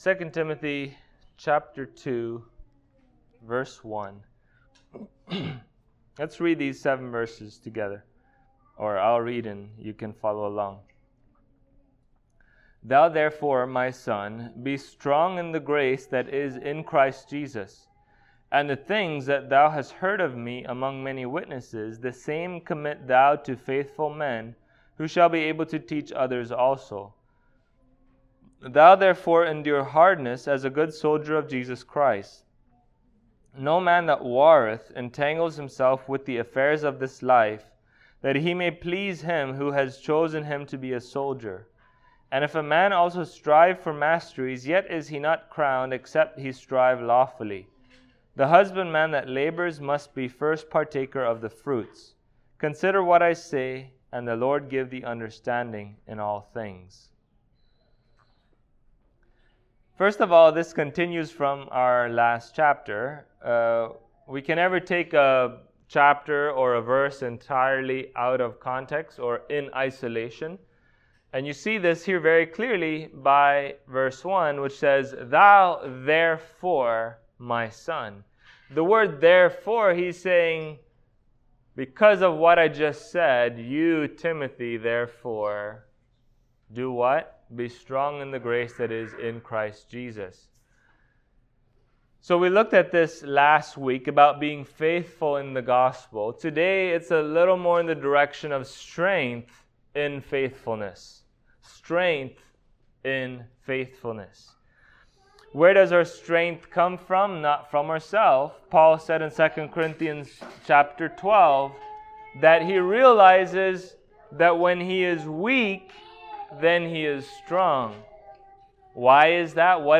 2 Timothy 2:1-7 Service Type: Sunday Morning There are many realities that we enjoy in the grace of God.